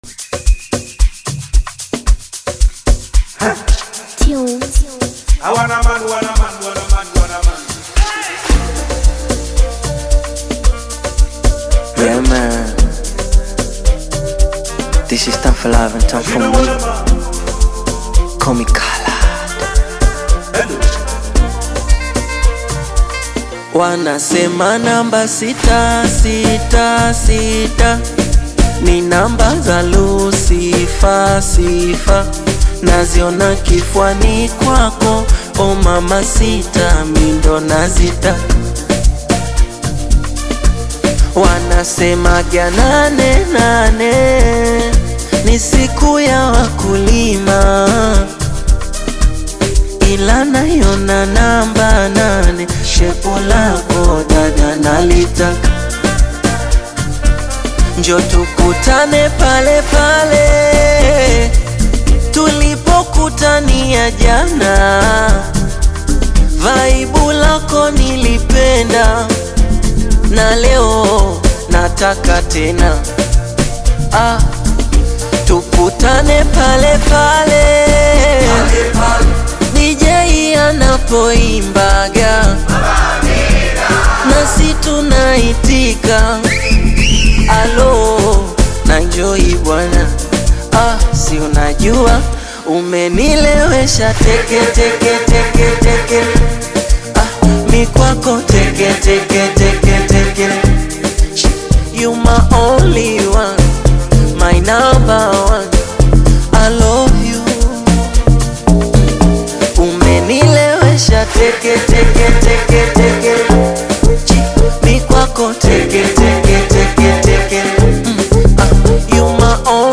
high-energy Amapiano/Bongo Flava anthem
Genre: Amapiano